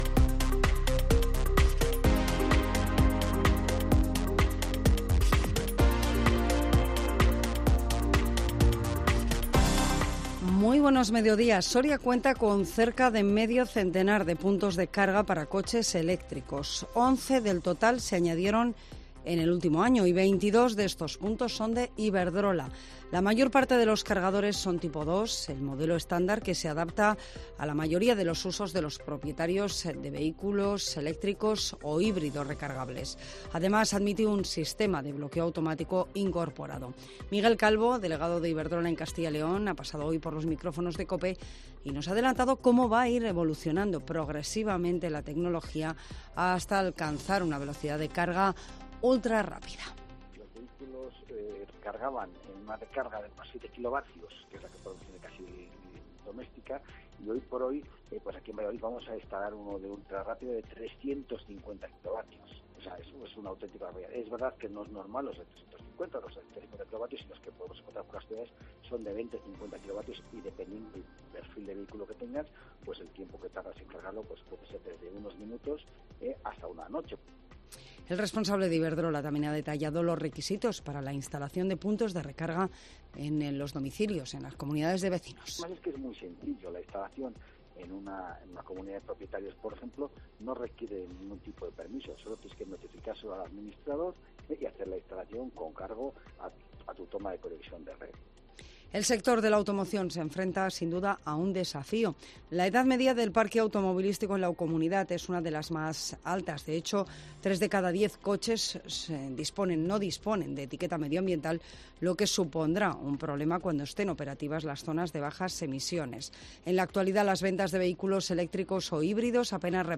Las noticias en COPE Soria